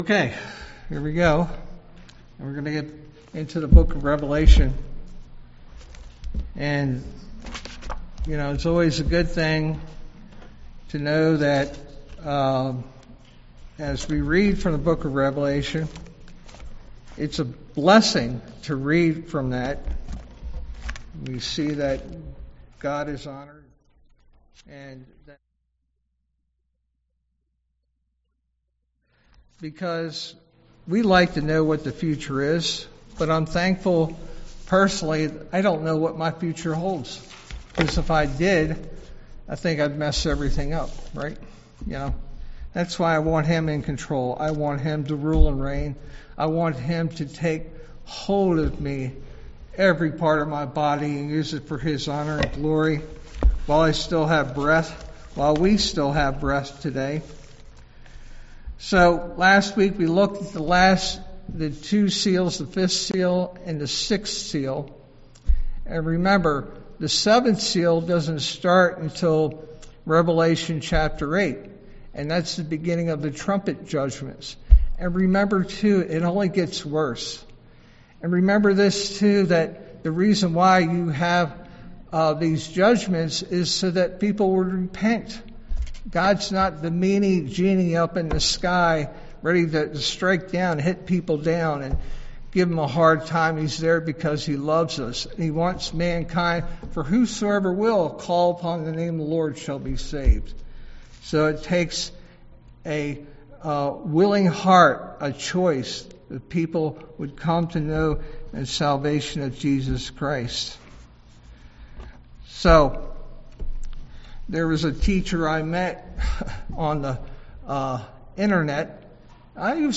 All Sermons Survivors of the Wrath of God 29 March 2026 Series